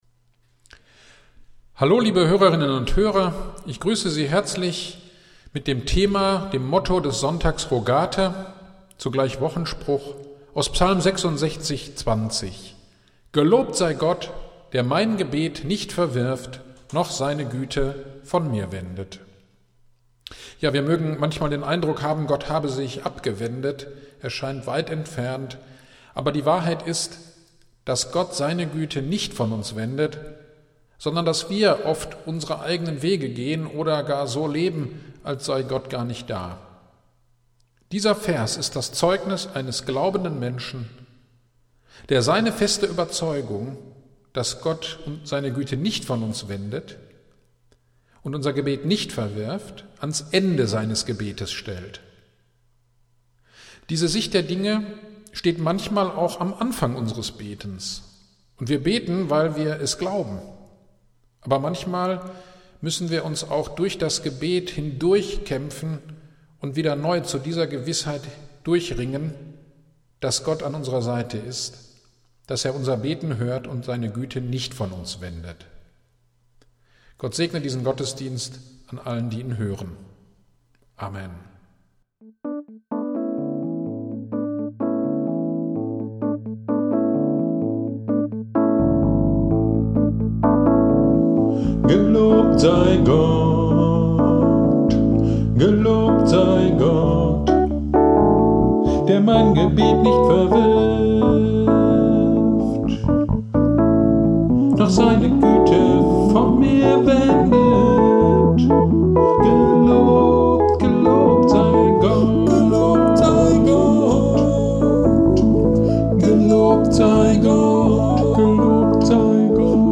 Gottesdienst am 09.05.2021 (Rogate) Thema: Neues Beten